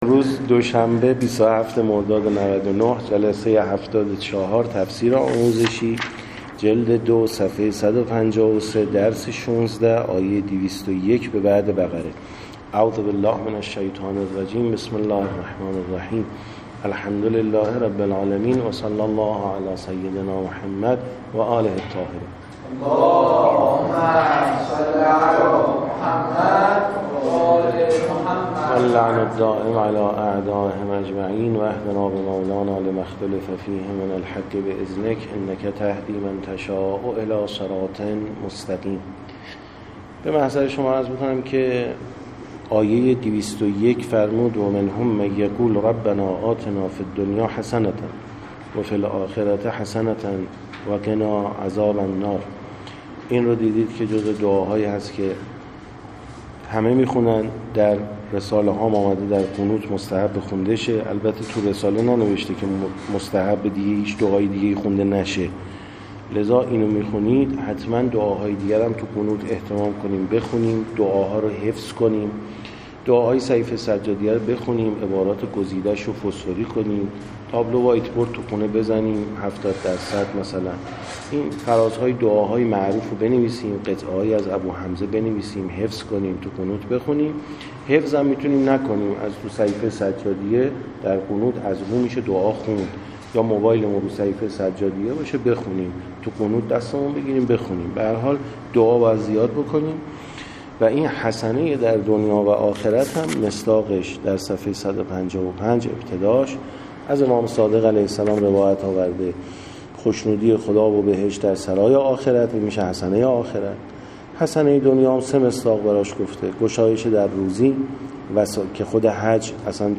74درس16ج2تفسیرآموزشی-ص153تا155-آیه201و202بقره.MP3